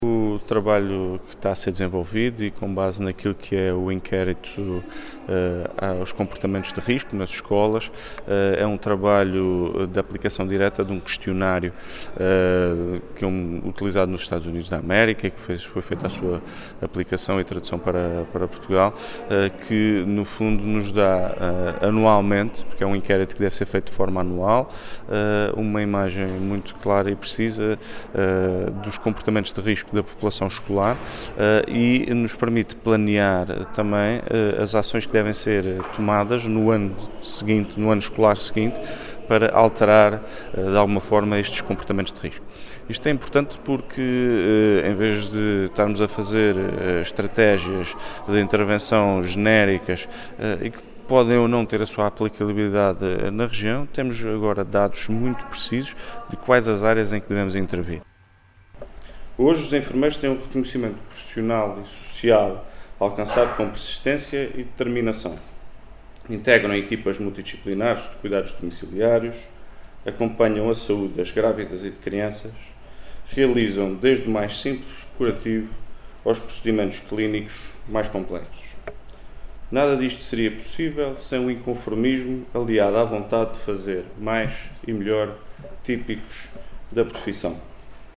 Luís Cabral, que falava em Angra do Heroísmo, na abertura VII Jornadas de Enfermagem do Hospital de Santo Espírito da Ilha Terceira, salientou que estes dados vão ser agora analisados pela Direção Regional de Saúde e servirão de base “à estratégia a implementar no combate à toxicodependência, à violência e à gravidez na adolescência”.